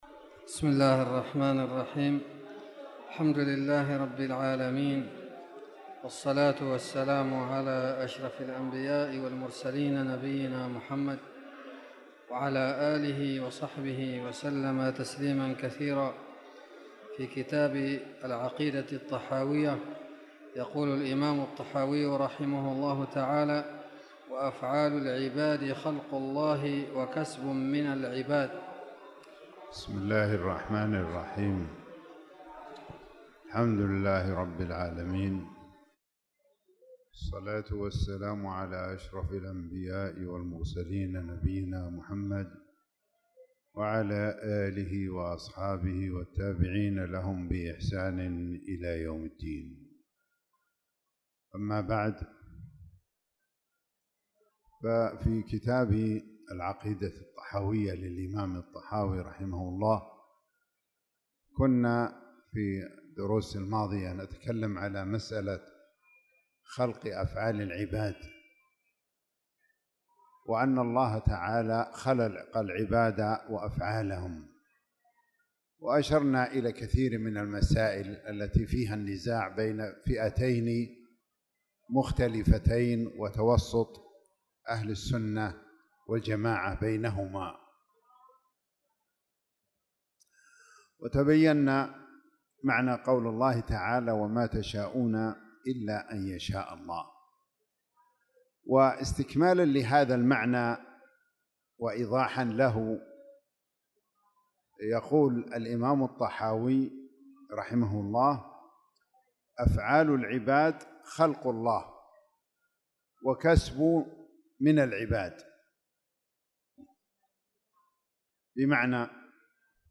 تاريخ النشر ٩ شعبان ١٤٣٧ هـ المكان: المسجد الحرام الشيخ